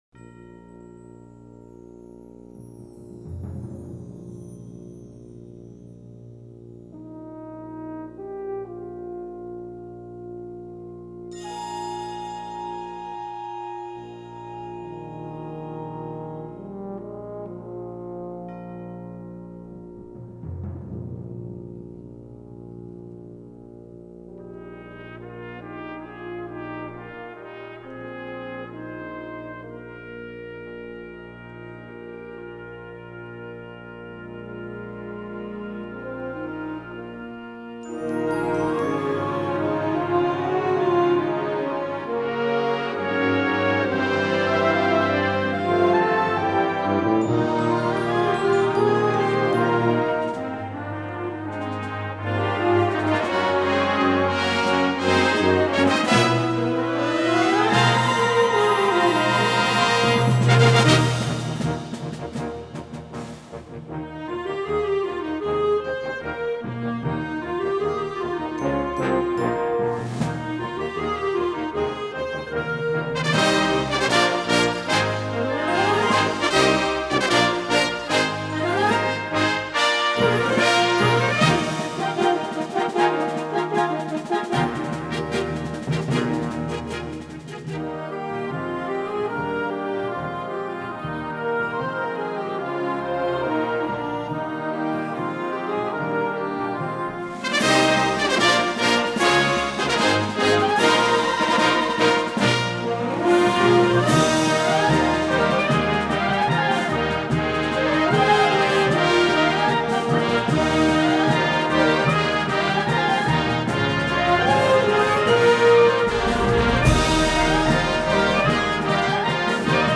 Gattung: Konzertwerk
Besetzung: Blasorchester